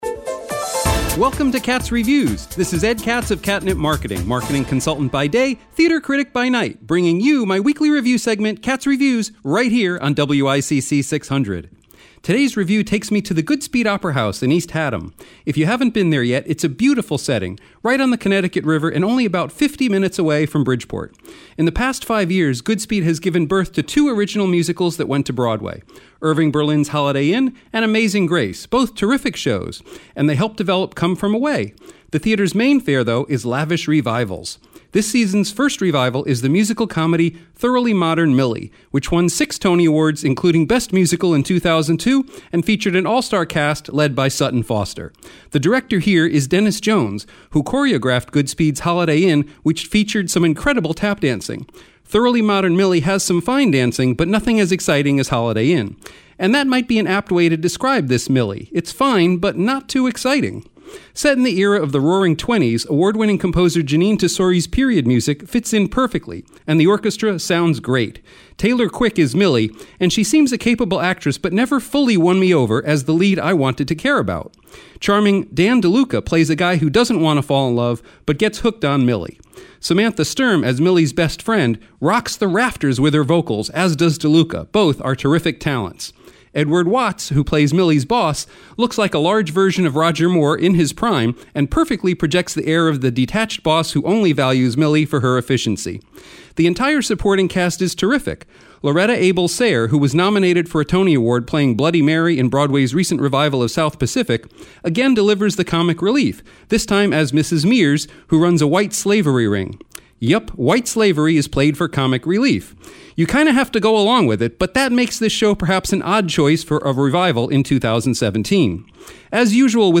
Connecticut / Theater Reviews
WICC-Audio-Review-5-23-17-Thoroughly-Modern-Millie-at-Goodspeed.mp3